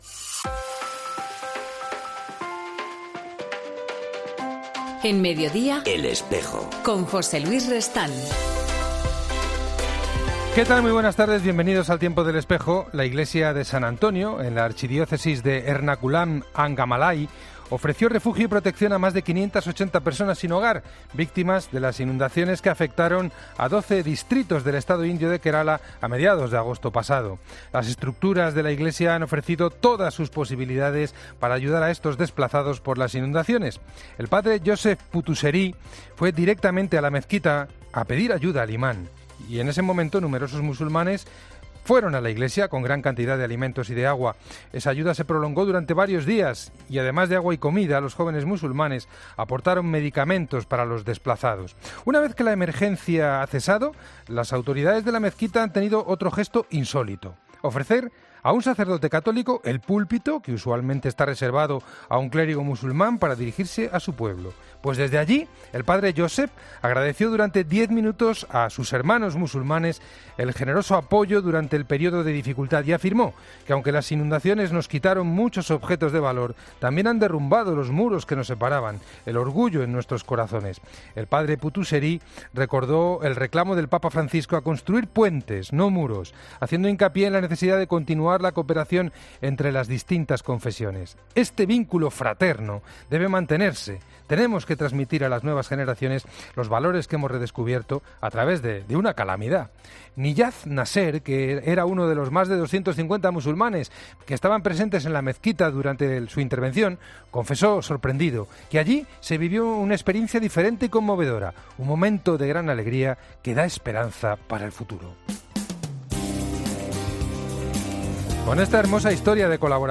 En 'El Espejo' de hoy volvemos nuestra mirada sobre la dramática situación que vive la República Centroafricana y lo hacemos con un testigo de excepción, el obispo de la Diócesis de Bangassou, al sur del país.